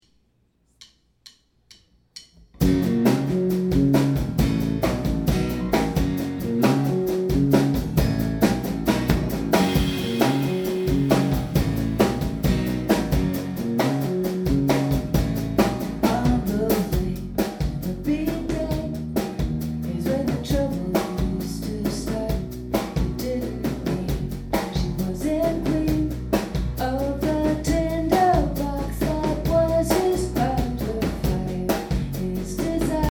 Great show; front row table at this intimate venue.